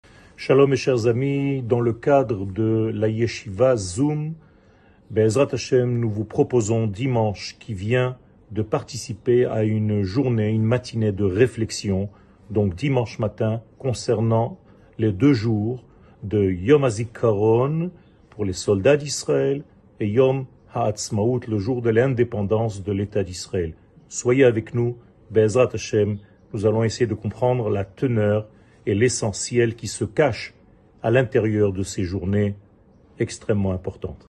שיעור מ 20 אפריל 2023